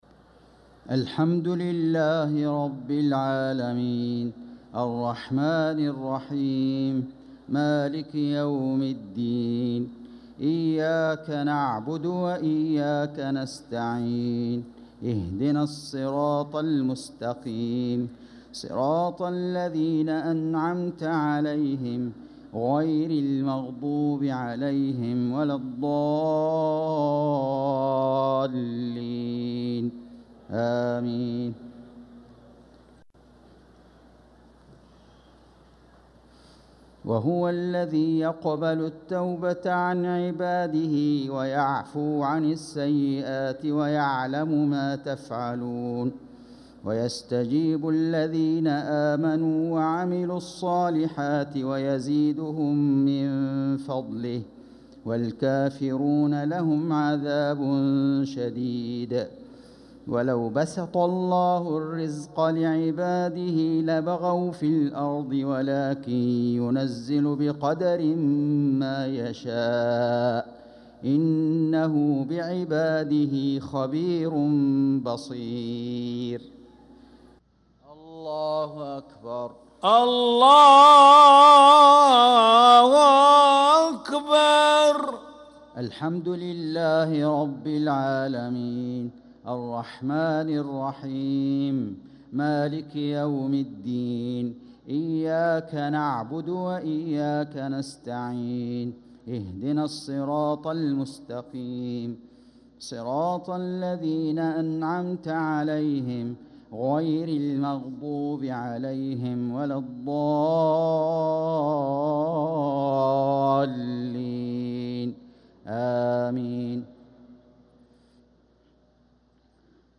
صلاة العشاء للقارئ فيصل غزاوي 25 صفر 1446 هـ
تِلَاوَات الْحَرَمَيْن .